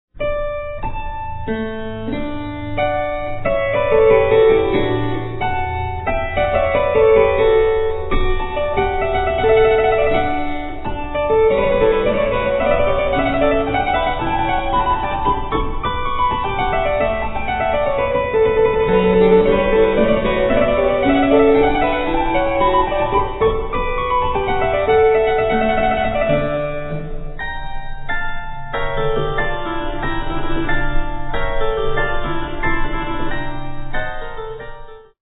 harpsichordist
Sonata for keyboard in D major, K. 118 (L. 122) - 6:11